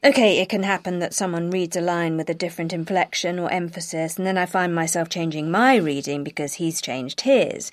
Now have a look at these examples where the /d/ is dropped because of elision and then the /n/ is assimilated into /m/.
| faɪm maɪˈself | (Cambridge Proficiency test).